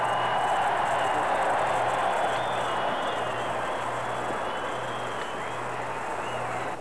cheer.wav